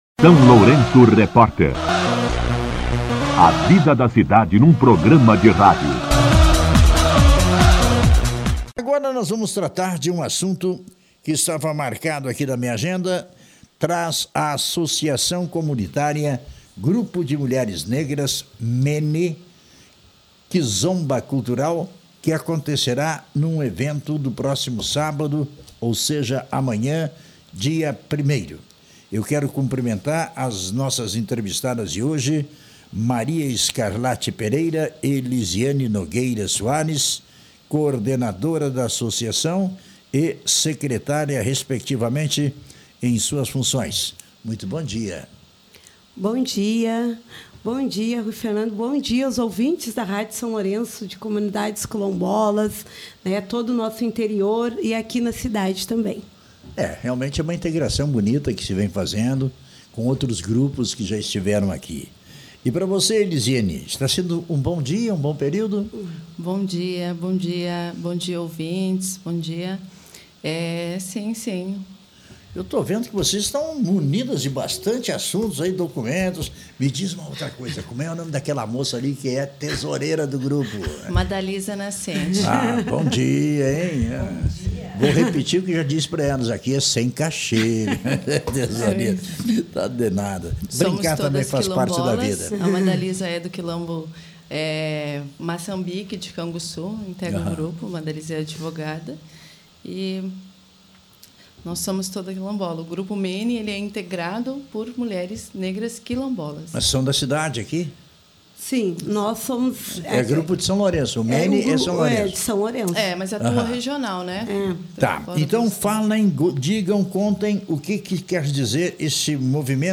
entrevistas-mulheres-quilombolas.mp3